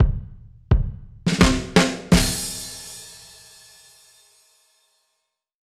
Db_DrumsA_Dry_85_04.wav